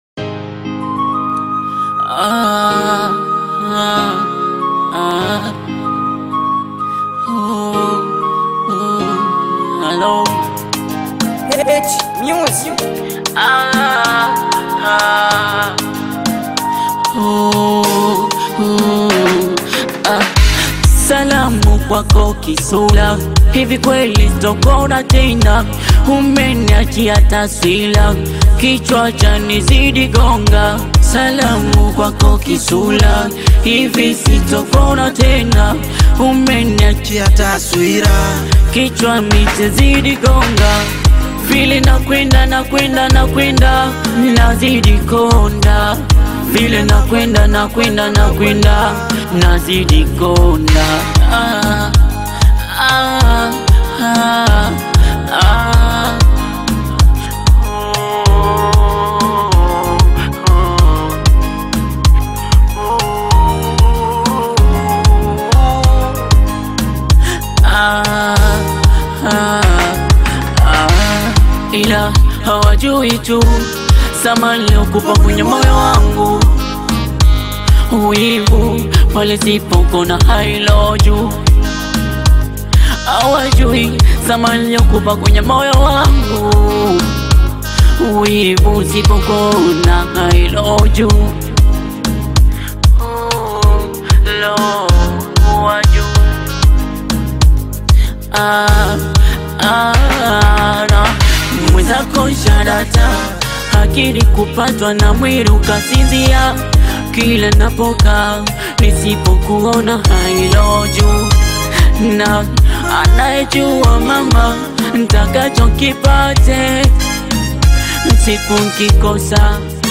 rich, soulful vocals